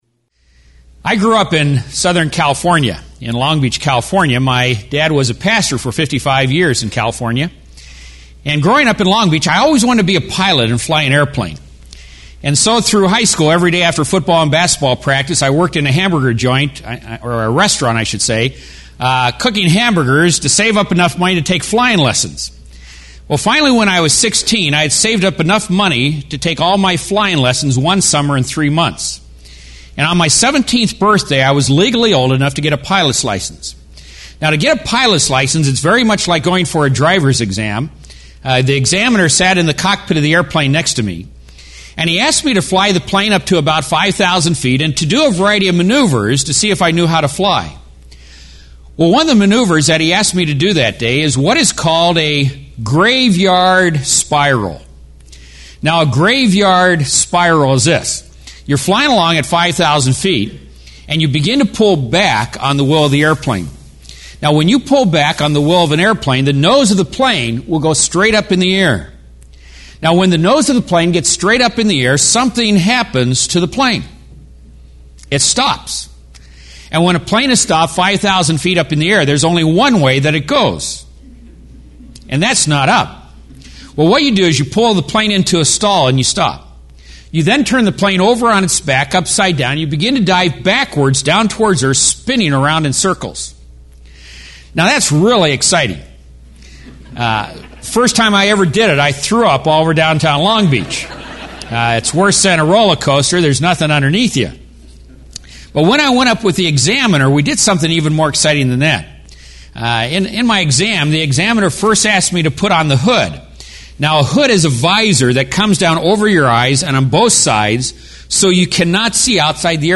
In this timely and stimulating lecture, the foundations of New Age Spirituality sweeping the world are laid bare.